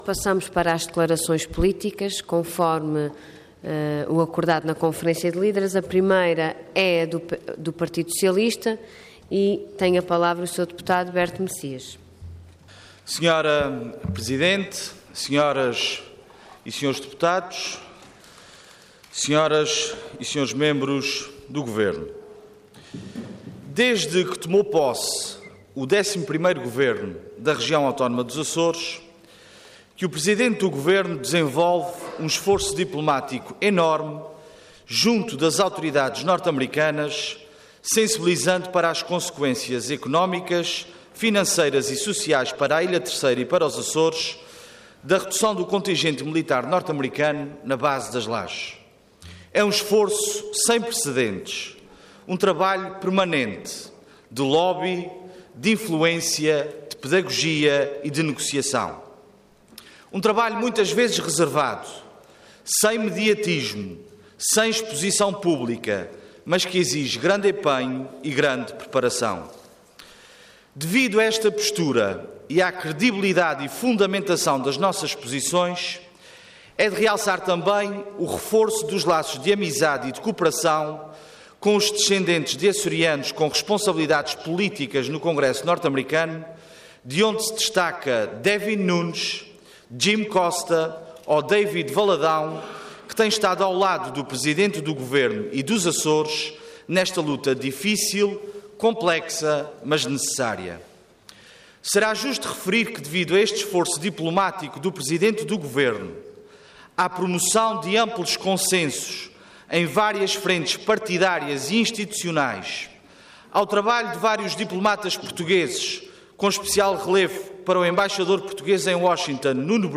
Parlamento online - Base das Lajes - Comissão Bilateral Permanente do Acordo de Cooperação e Defesa E.U.A. - Portugal
Detalhe de vídeo 18 de junho de 2015 Download áudio Download vídeo Processo X Legislatura Base das Lajes - Comissão Bilateral Permanente do Acordo de Cooperação e Defesa E.U.A. - Portugal Intervenção Declaração Política Orador Berto Messias Cargo Deputado Entidade PS